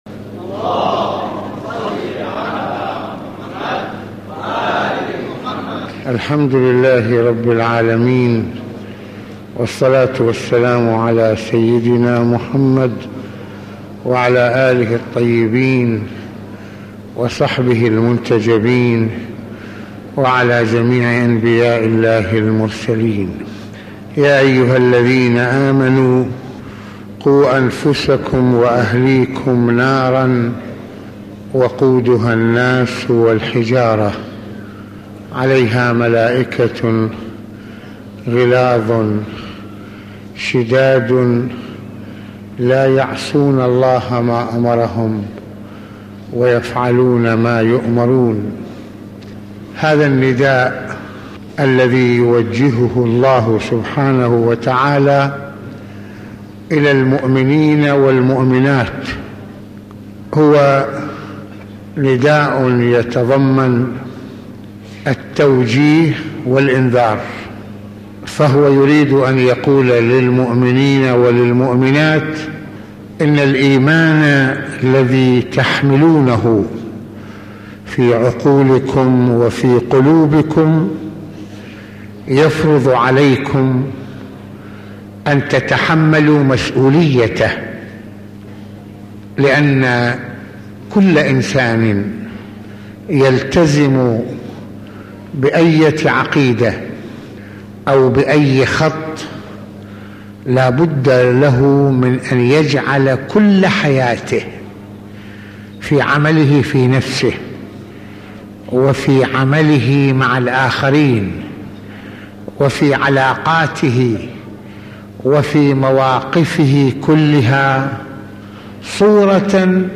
ملفات وروابط - المناسبة : موعظة ليلة الجمعة المكان : مسجد الإمامين الحسنين (ع) المدة : 23د | 33ث المواضيع : معنى الالتزام بالايمان - دور الاهل في رعاية الايناء من الوقوع في المحرمات - التوبة وشروطها